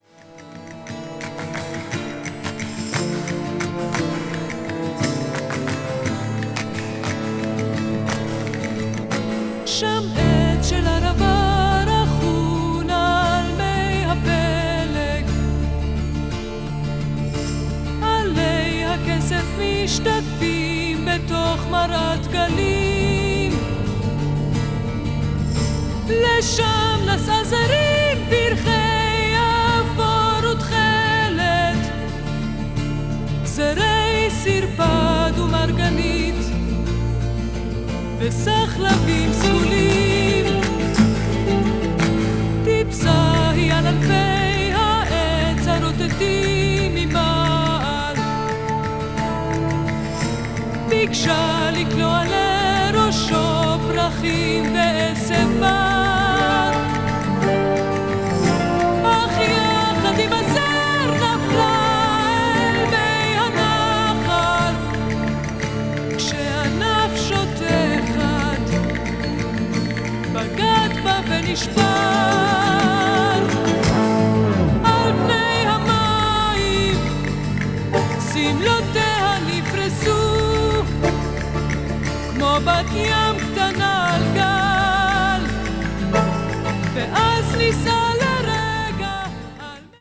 a.o.r.   israeli pop   mellow groove